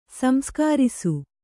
♪ samskārisu